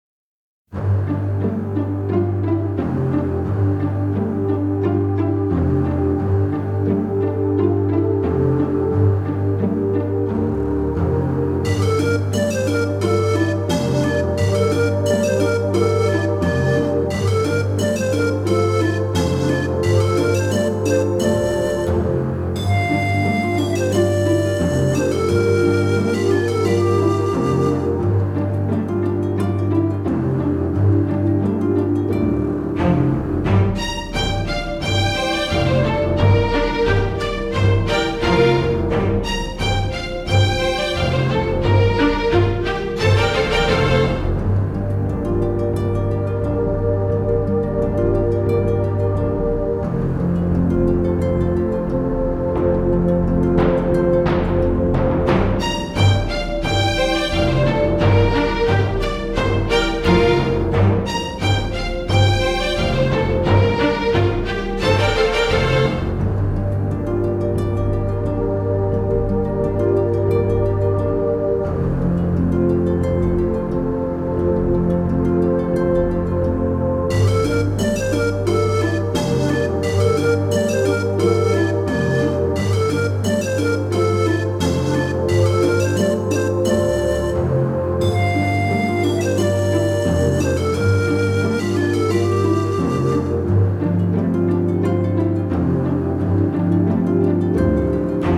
洞窟や遺跡系のダンジョンBGMです。コーラスがちょっと神秘的。